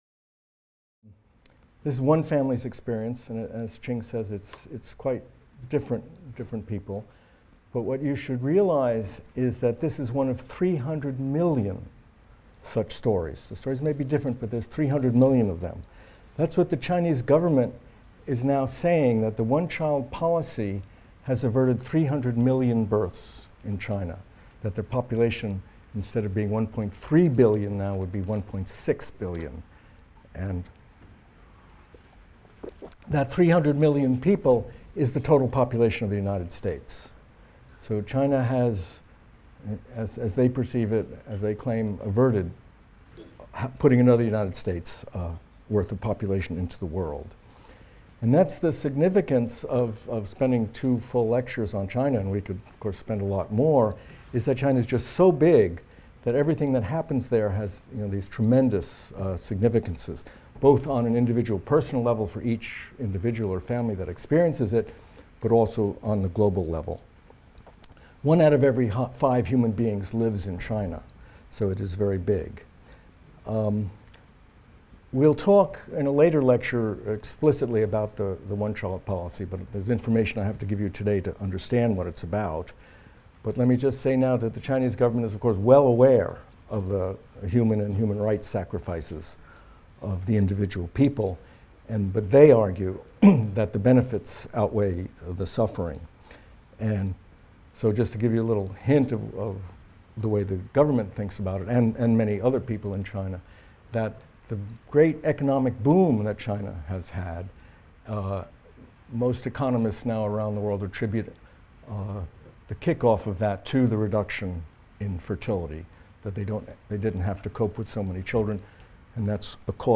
MCDB 150 - Lecture 16 - Population in Traditional China | Open Yale Courses